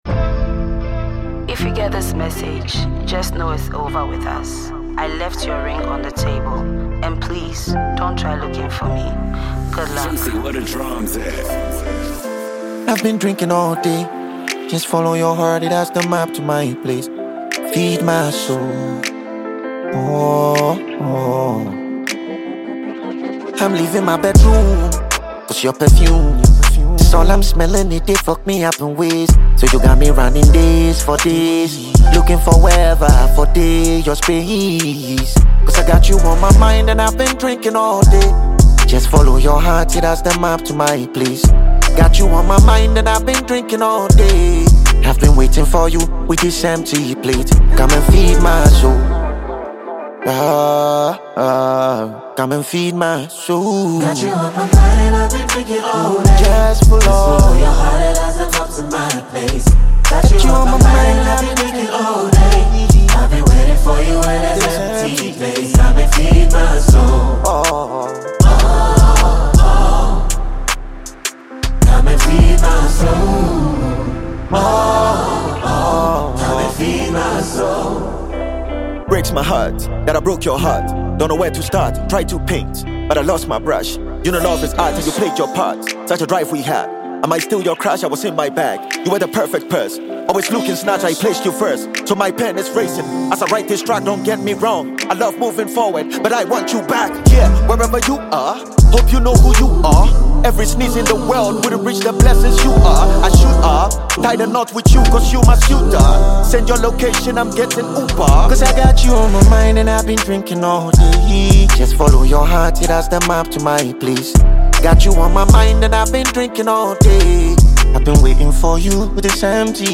Ghanaian award-winning rapper